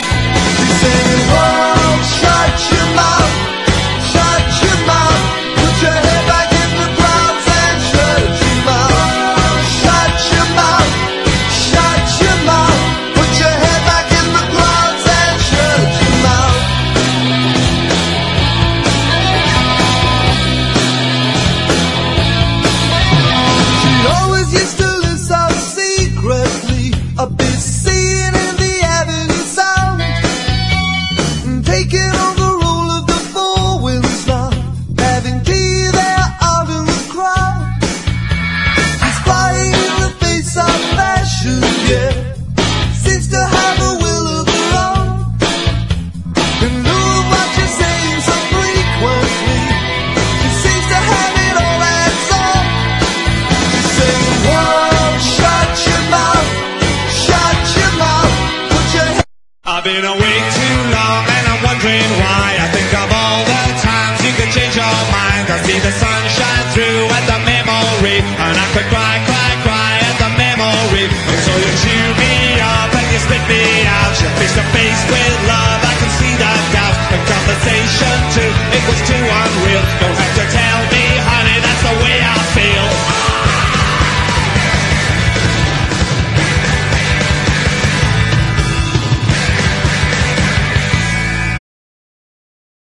¥980 (税込) ROCK / 80'S/NEW WAVE. / FUNK-A-LATINA
切ないメロディーが胸を締め付ける大ヒット曲
グルーヴィなギター・カッティングにうねるスラップ・ベース、爽快ホーンにスティール・パンも軽やかに転がる
オールドスクールなラップも入る